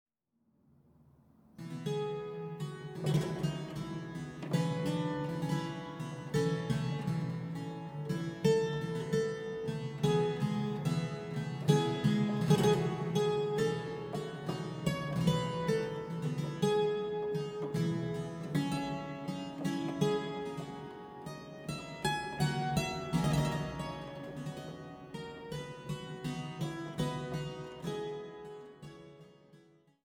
Fuge b-Moll